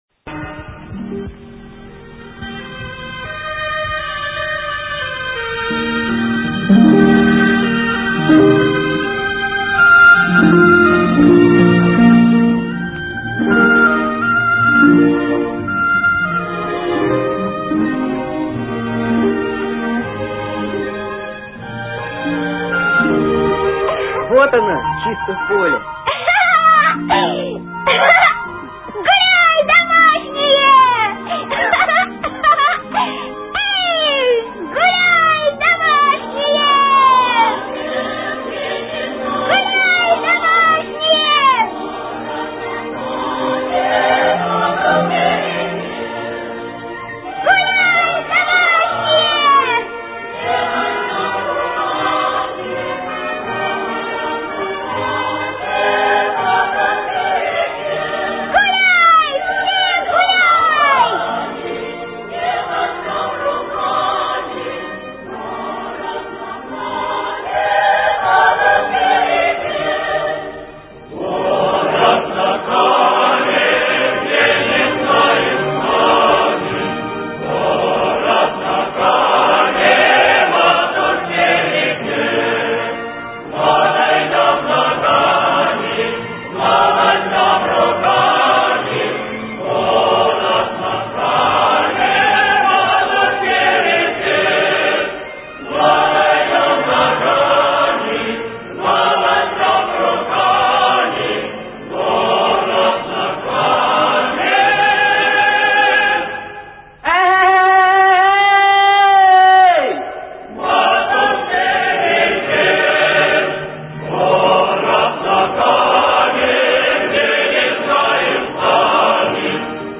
ראשית,  הסולם המז'ורי במקור, הפך להיות מינור שבמינור.
השיר ברוסית מתוך פסקול הסרט